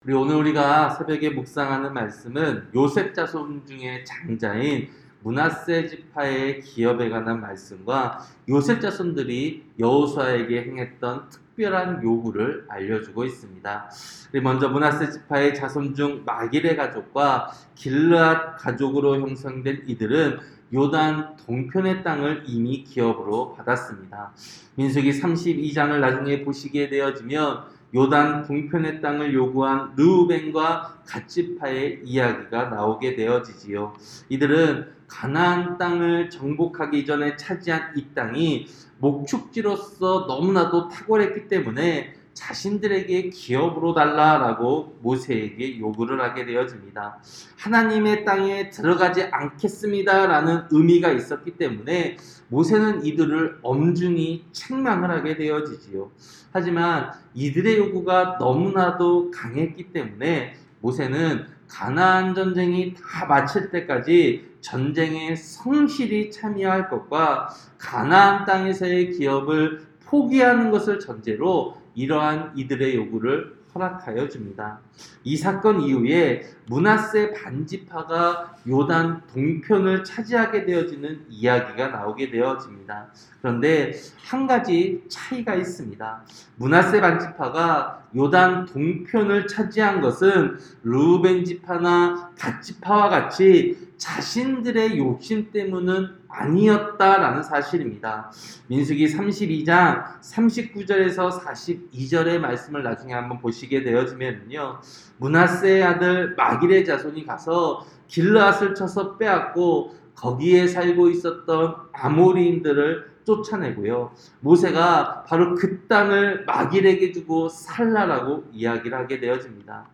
새벽설교-여호수아 17장